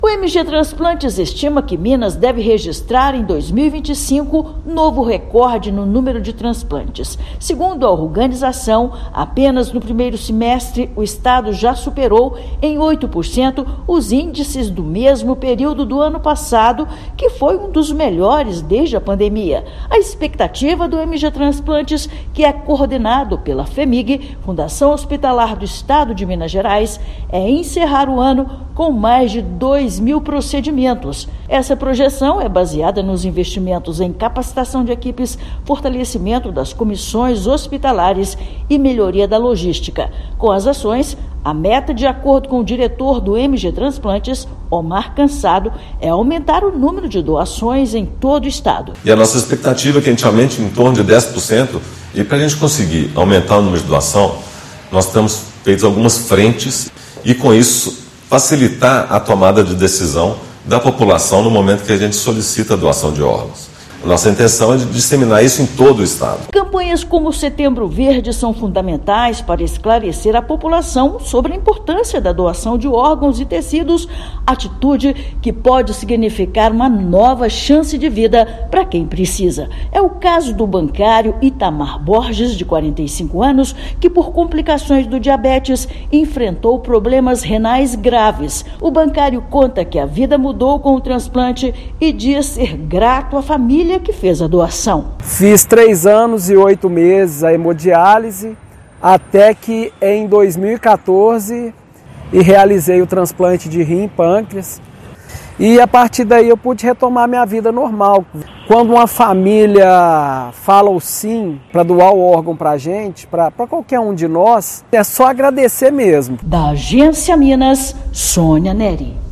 [RÁDIO] Minas Gerais projeta recorde em transplantes em 2025 com investimentos em capacitação e logística
Setembro Verde mobiliza e reforça o papel da sociedade no enfrentamento à recusa familiar, ainda um dos principais desafios da doação. Ouça matéria de rádio.